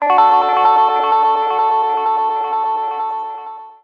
描述：三和弦D和弦用电吉他演奏，带点失真和强烈的短时延时，
Tag: 和弦 d 吉他 加工